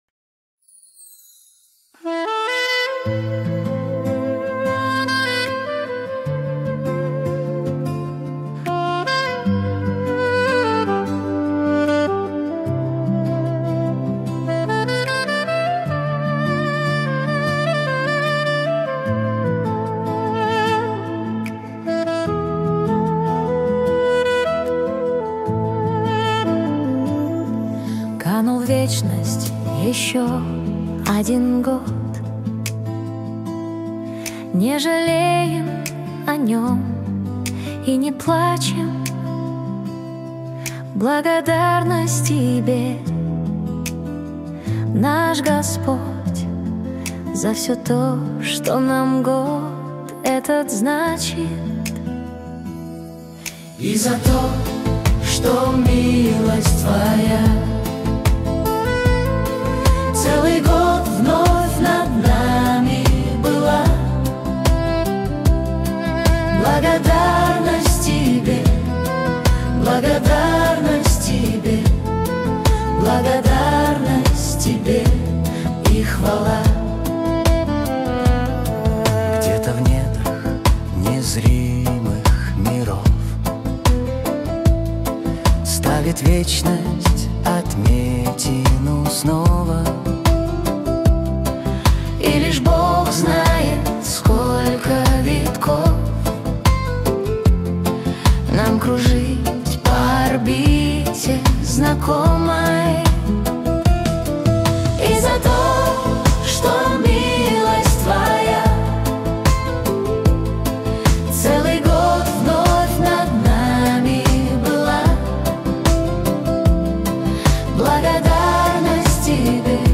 песня ai
93 просмотра 234 прослушивания 25 скачиваний BPM: 75